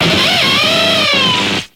Grito de Luxray.ogg
Grito_de_Luxray.ogg.mp3